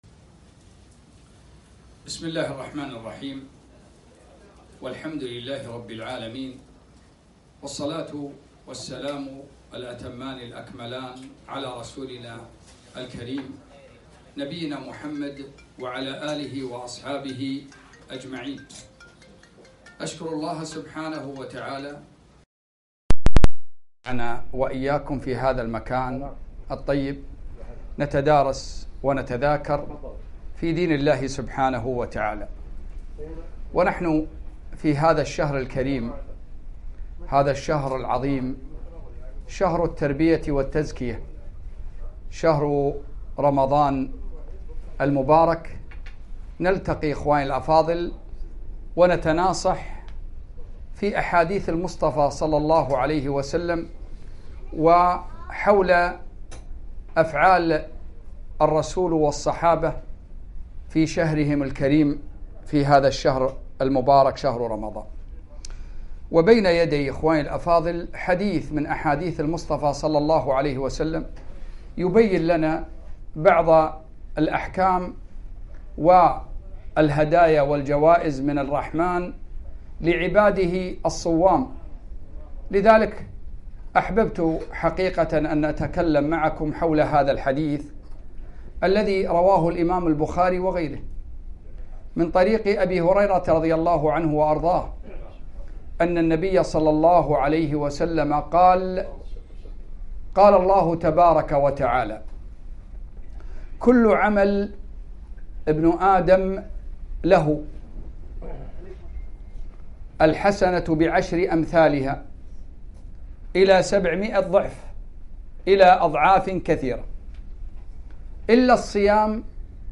محاضرة - إلا الصوم فإنه لي وأنا أجزي به